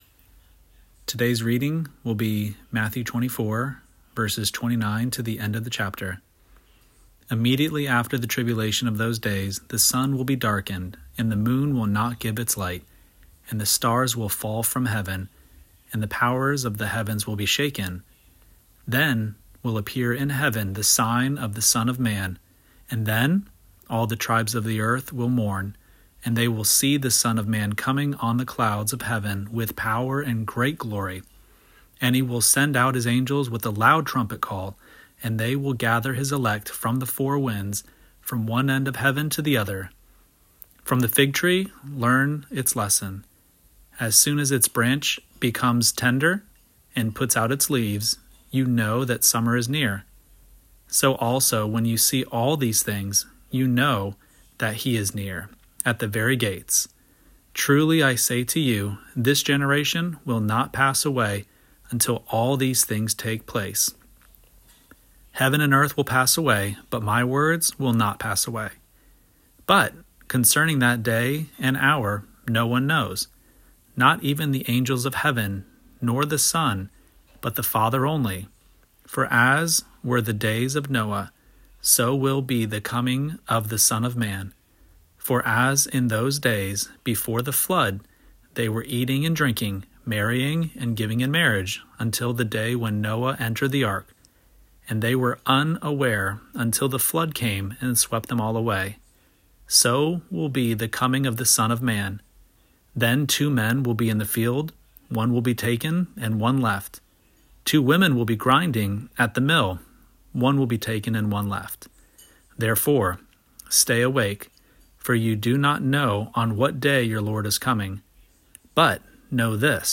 Daily Bible Reading (ESV)